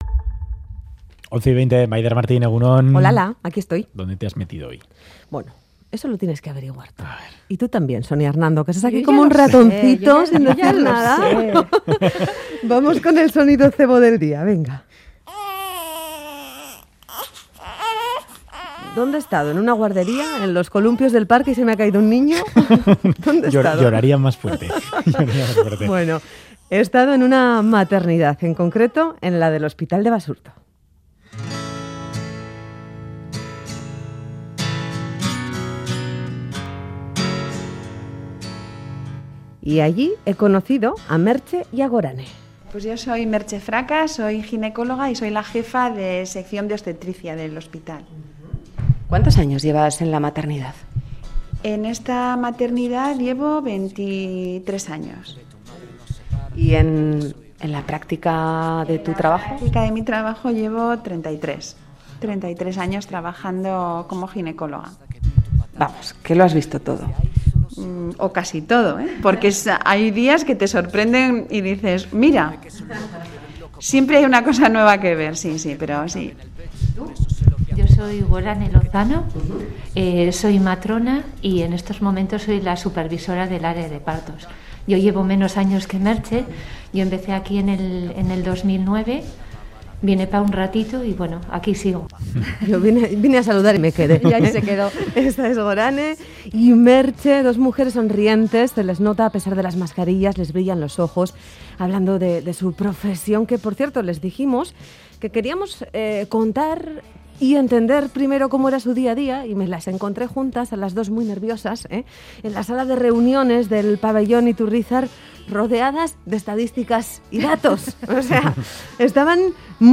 Radio Euskadi recorre el pabellón de maternidad del Hospital de Basurto para conocer su día a día